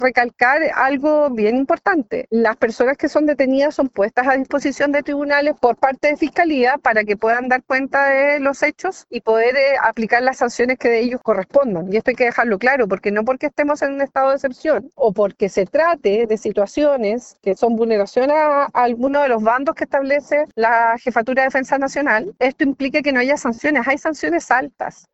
Por su parte, la seremi de Seguridad Pública, Paulina Stuardo, indicó que los detenidos han sido puestos a disposición de los tribunales y que existen sanciones severas para quienes no respeten la normativa vigente.